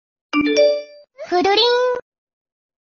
Nada notifikasi WA Kururing
Kategori: Nada dering
Keterangan: Nada notifikasi WA lucu Kururing kini viral di TikTok.
nada-notifikasi-wa-kururing-id-www_tiengdong_com.mp3